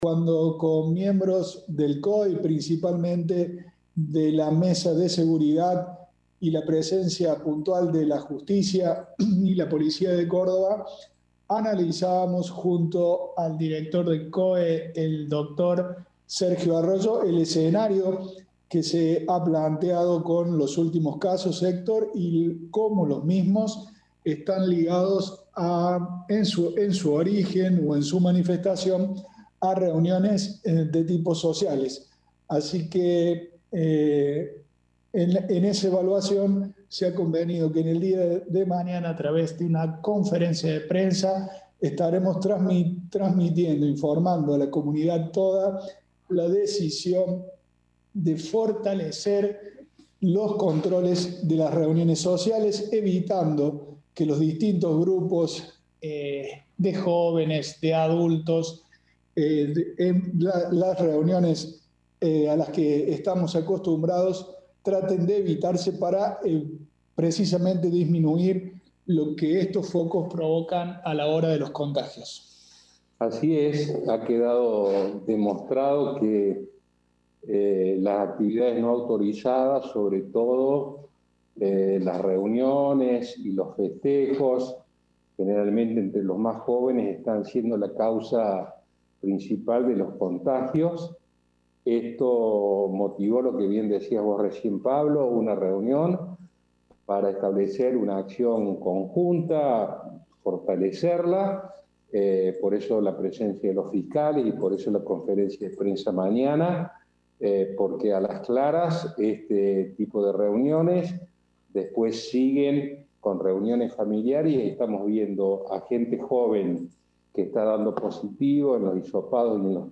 En el informe de la noche del jueves, el intendente interino Pablo Rosso y el jefe de gabinete Héctor Muñoz se refirieron a este tema.